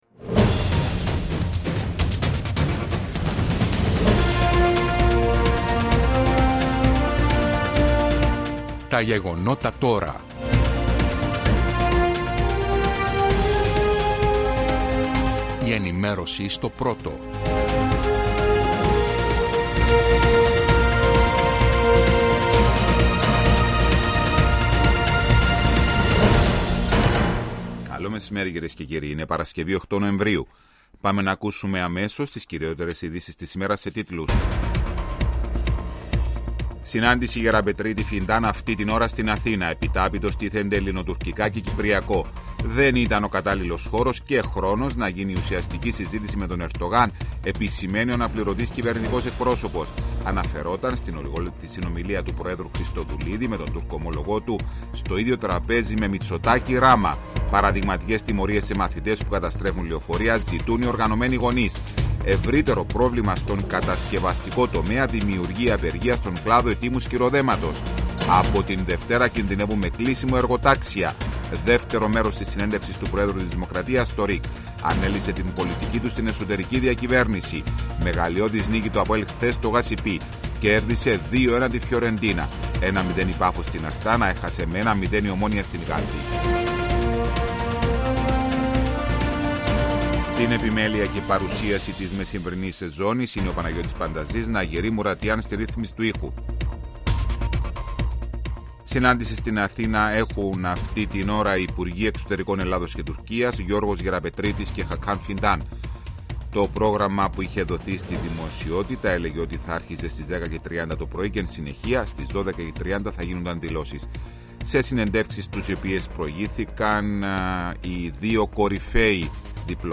Η επικαιρότητα της ημέρας αναλυτικά με ρεπορτάζ, συνεντεύξεις και ανταποκρίσεις από Κύπρο και εξωτερικό.